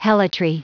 Prononciation du mot helotry en anglais (fichier audio)